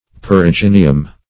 perigynium - definition of perigynium - synonyms, pronunciation, spelling from Free Dictionary
Search Result for " perigynium" : The Collaborative International Dictionary of English v.0.48: Perigynium \Per`i*gyn"i*um\, n.; pl.